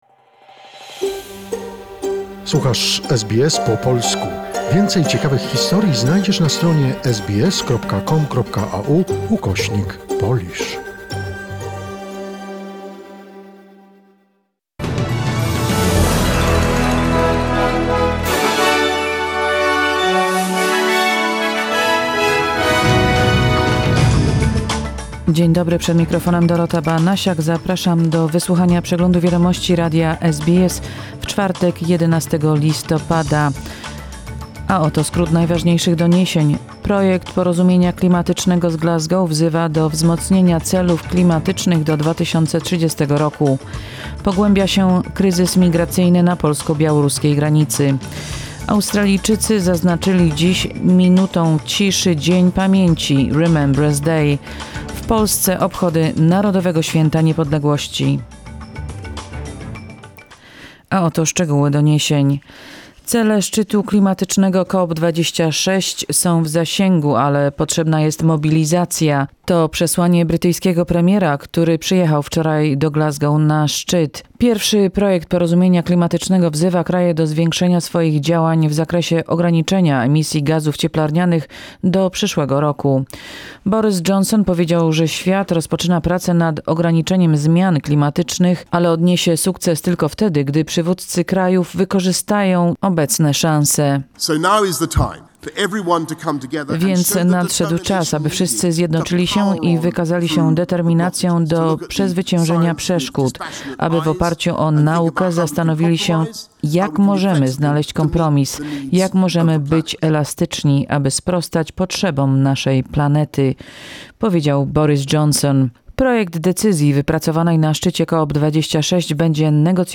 SBS News Flash in Polish, 11 November 2021